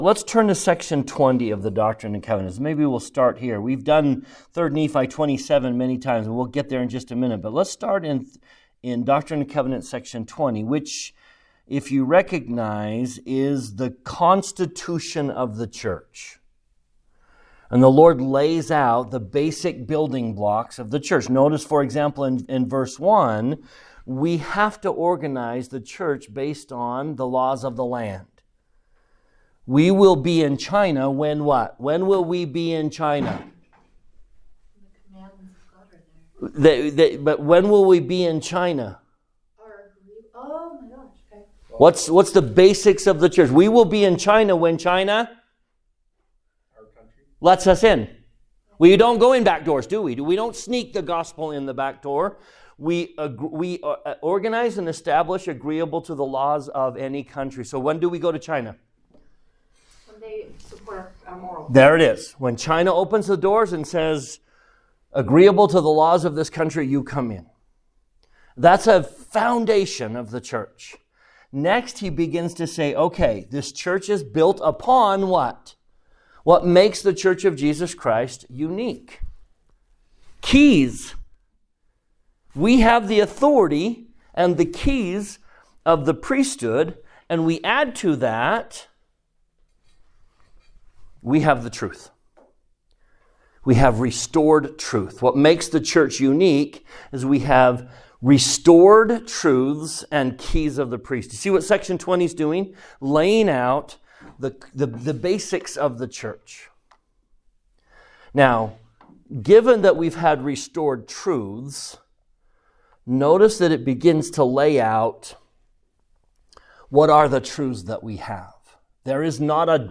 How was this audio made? This podcast contains the audio versions of the classes he is currently teaching (each season is a separate class) and is mainly intended for Institute students ages 18-35.